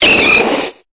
Cri de Machoc dans Pokémon Épée et Bouclier.